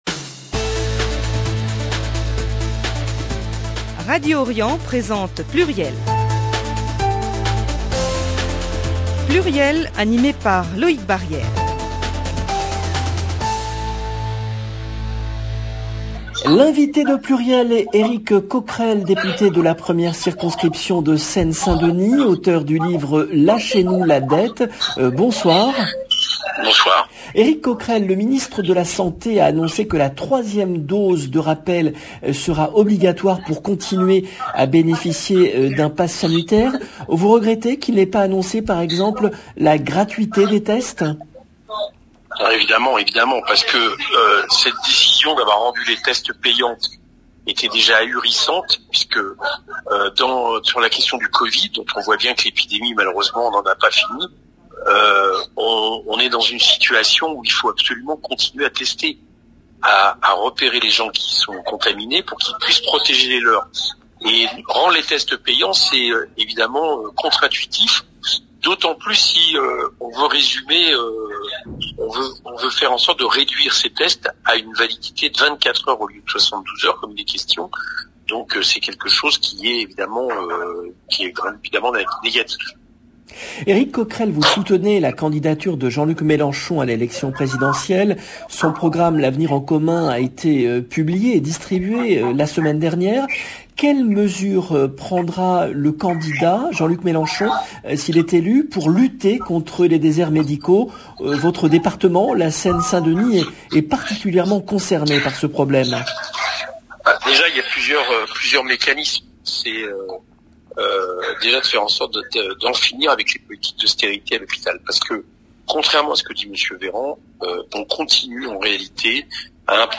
Eric Coquerel, député La France Insoumise
L’invité de PLURIEL est Eric Coquerel, député LFI de la 1ère circonscription de Seine-Saint-Denis, auteur du livre « Lâchez-nous la dette ! »